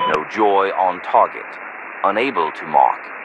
Radio-jtacSmokeNoTarget3.ogg